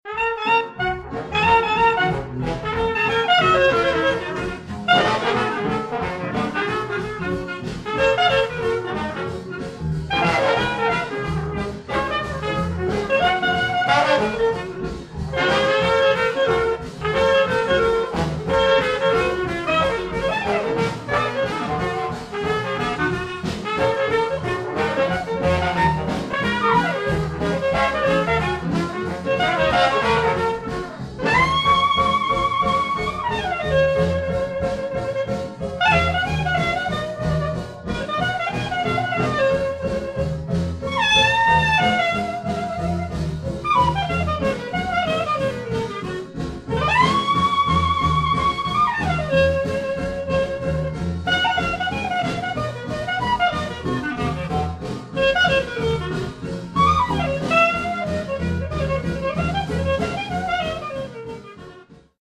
trumpet
piano